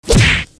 s_crash.mp3